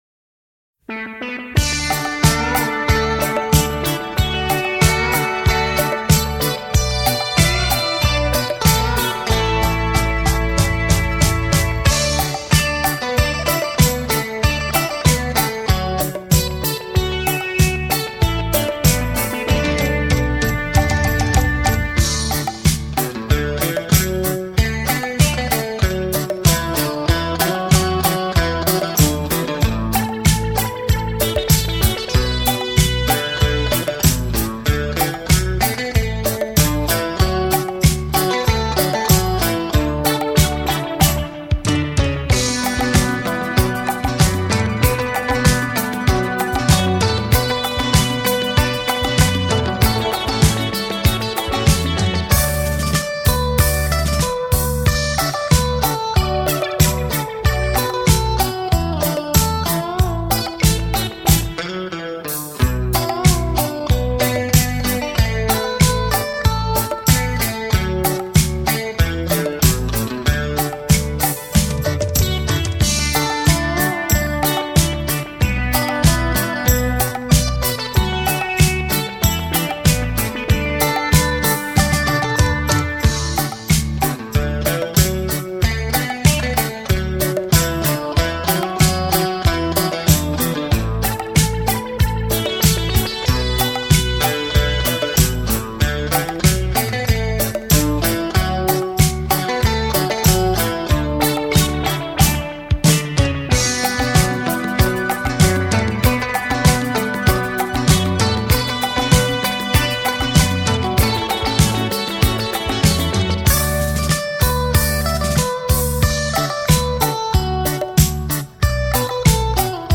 名典音乐系列 双吉他